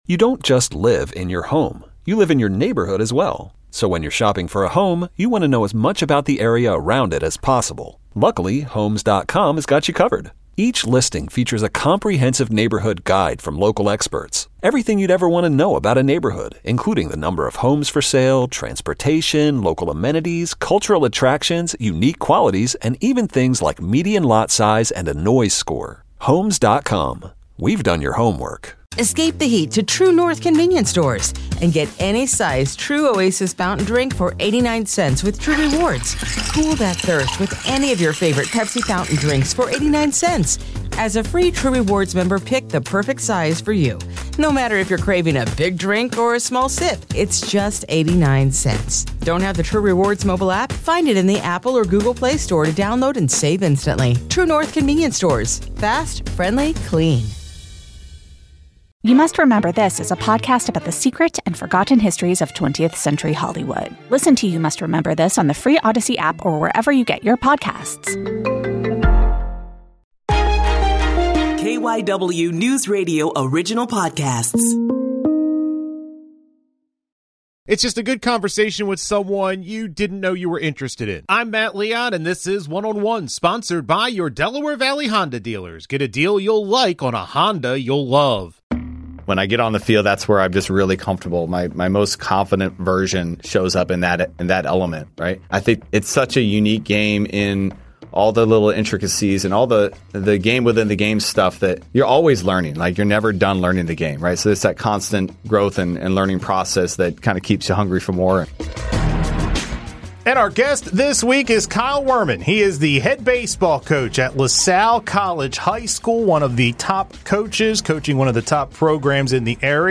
in studio to talk about his life in baseball.